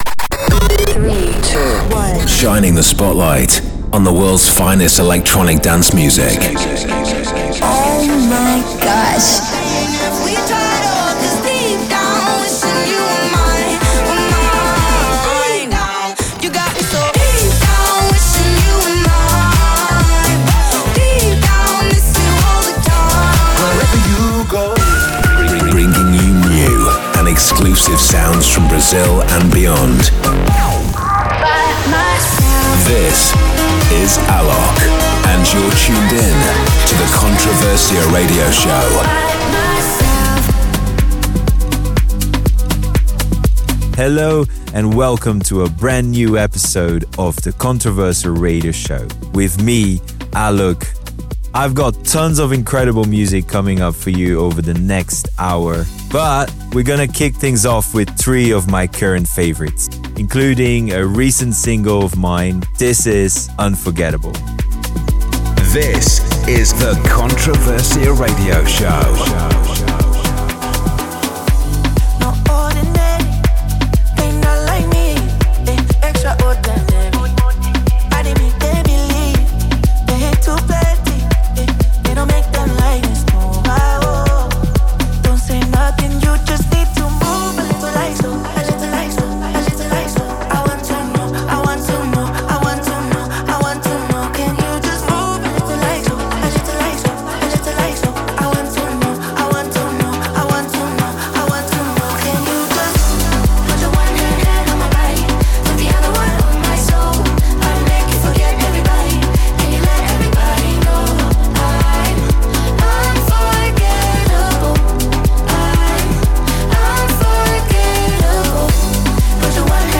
Also find other EDM Livesets, DJ Mixes and Radio Show
The weekly radio show